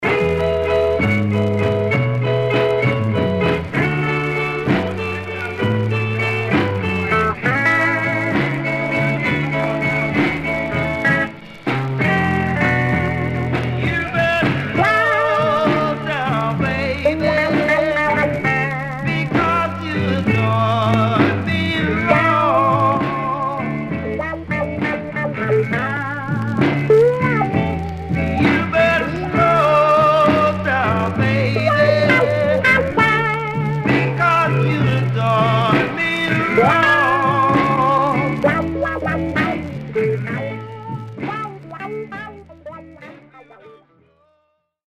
Stereo/mono Mono
Soul